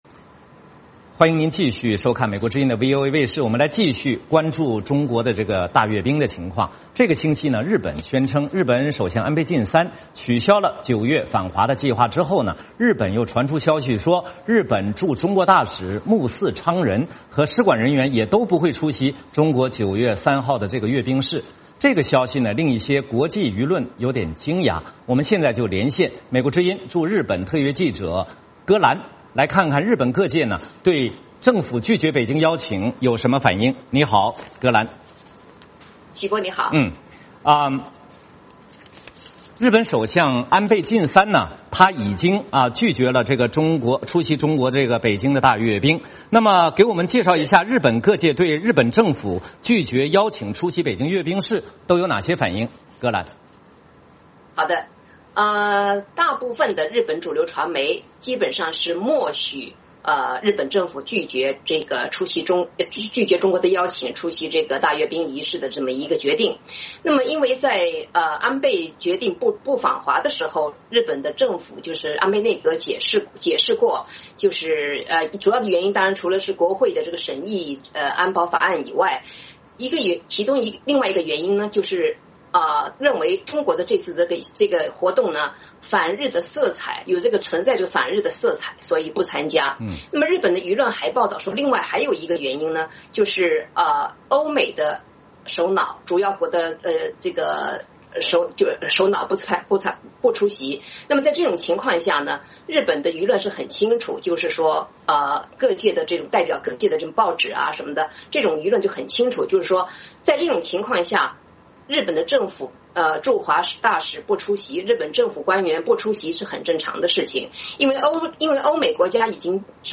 VOA连线：日本舆论评政府拒绝北京大阅兵